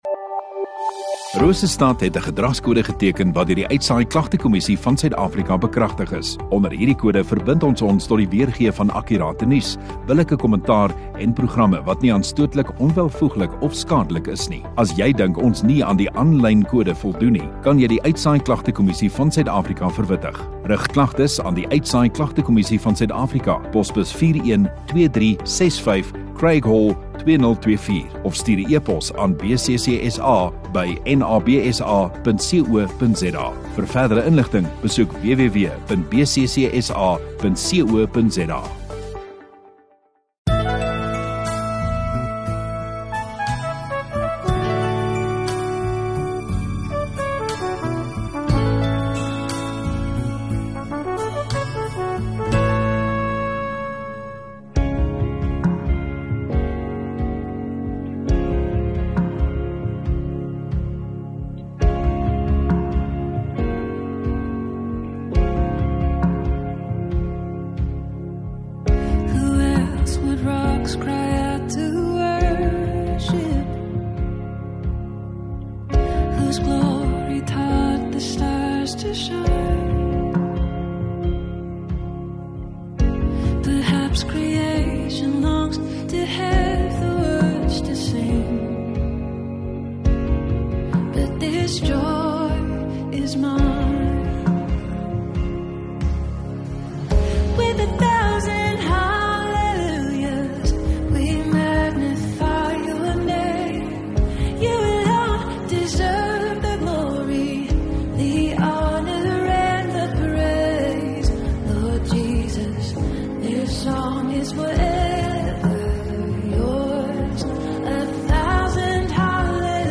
26 Jan Sondagaand Erediens